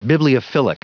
Prononciation du mot bibliophilic en anglais (fichier audio)